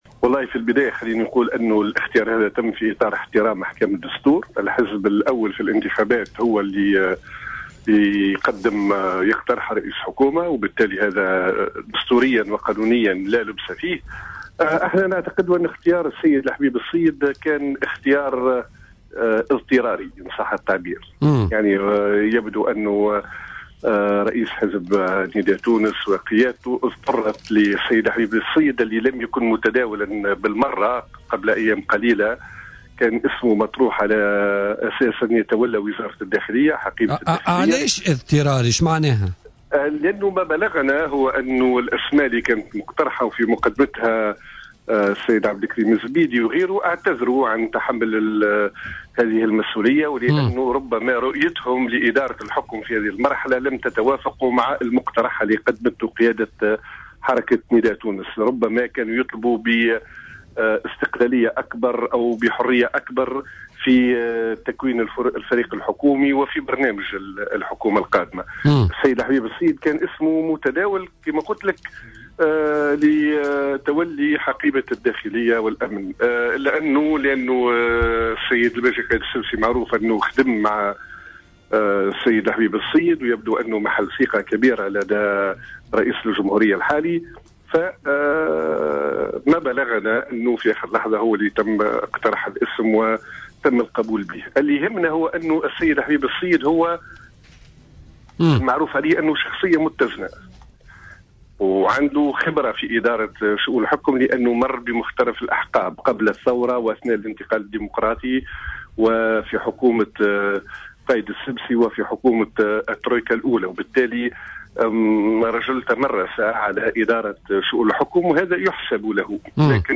قال الناطق الرسمي باسم الحزب الجمهوري عصام الشابي،اليوم في مداخلة له في برنامج "بوليتيكا" إن تعيين الحبيب الصيد رئيسا للحكومة كان اختيارا اضطراريا باعتبار وأن مجمل الأسماء التي تم اقتراحها اعتذرت عن تولي هذا المنصب.